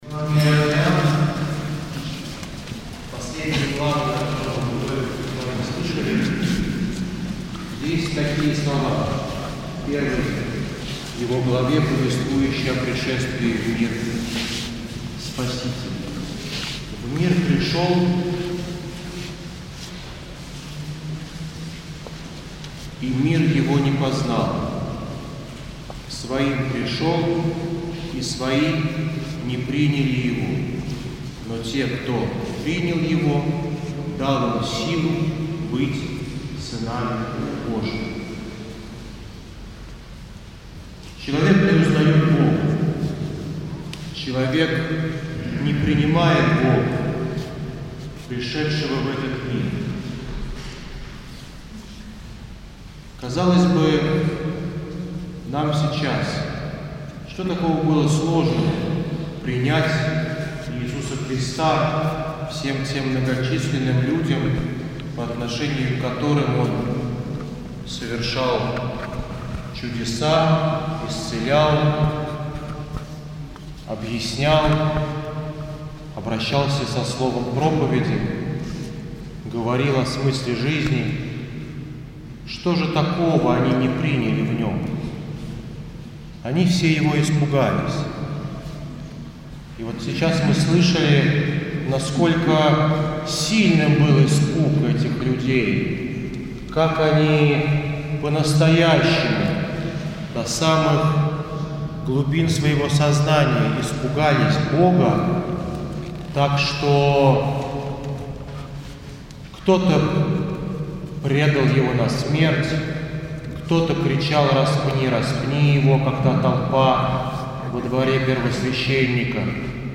Проповедь после 4 пассии акафиста страстям Христовым